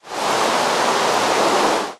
rain2.ogg